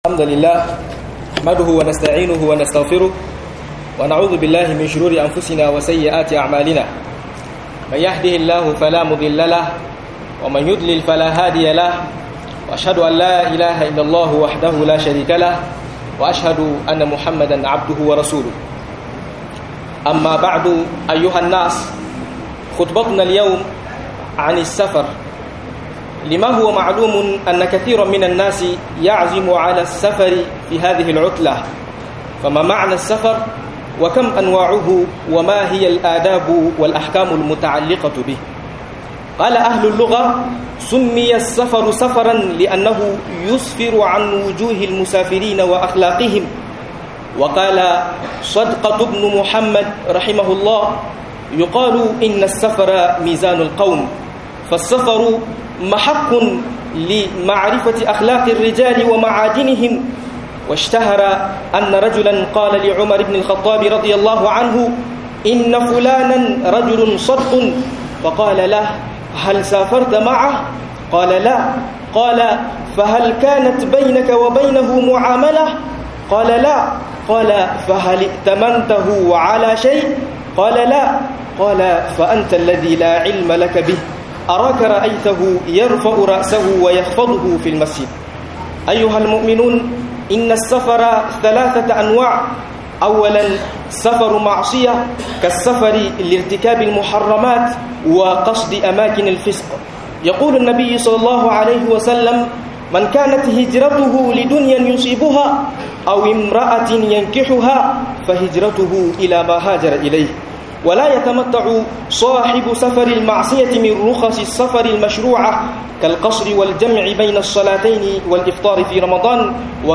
(balaguro) Ladubba da Hukunce hukuce - MUHADARA